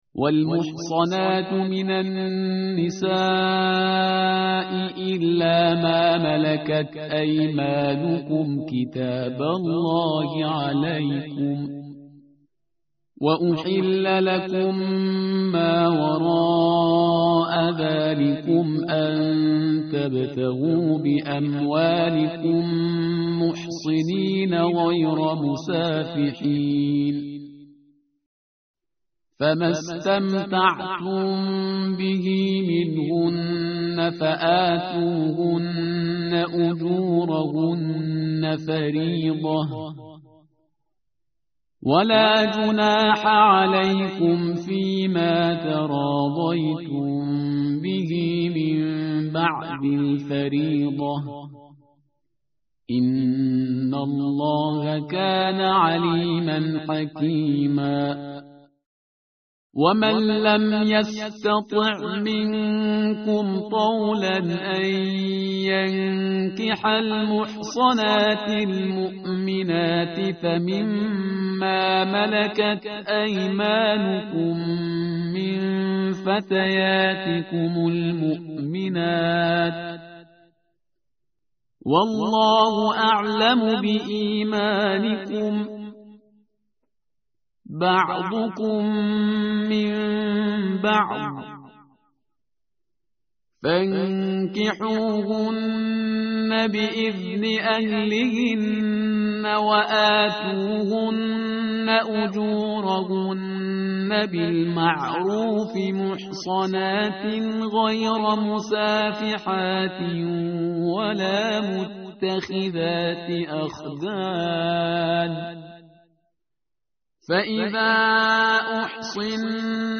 متن قرآن همراه باتلاوت قرآن و ترجمه
tartil_parhizgar_page_082.mp3